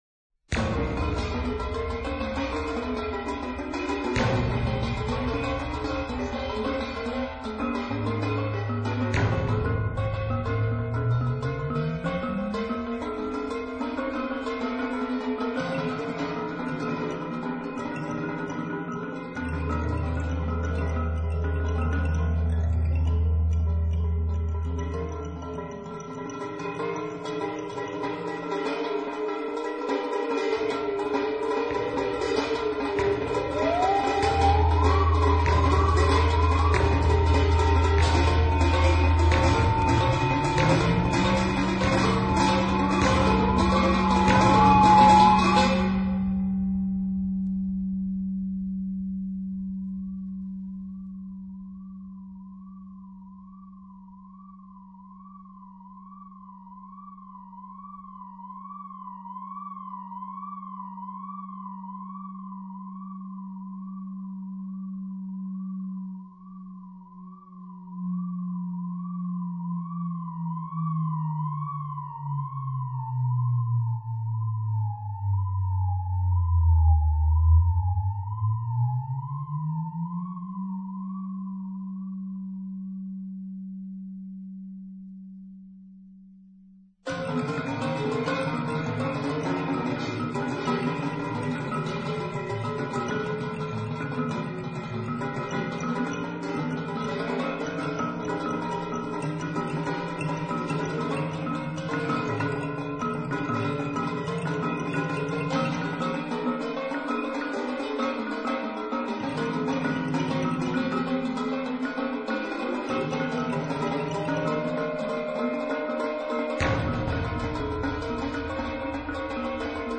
Maraton soudobé hudby 2004